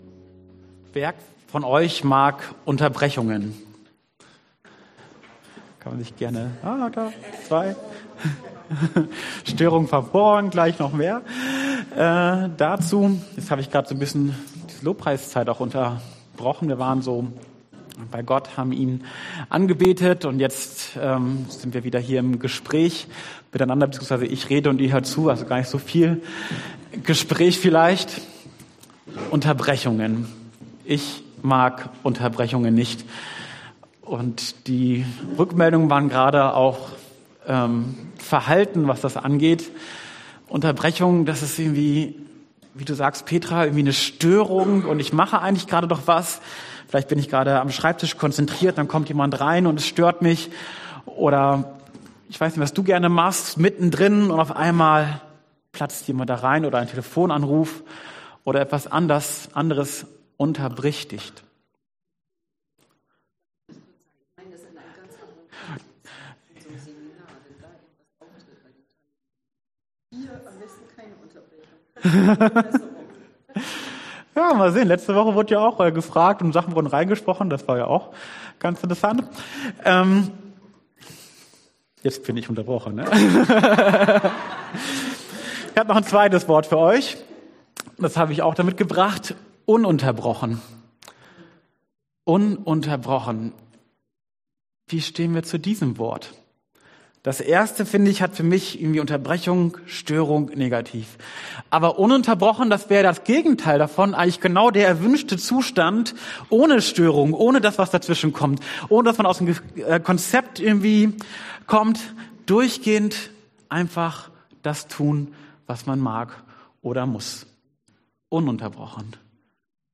der mich sieht Passage: 1. Mose 21,12-20 Dienstart: Predigt « Die Reich Gottes Perspektive Wie lang soll ich warten Gott?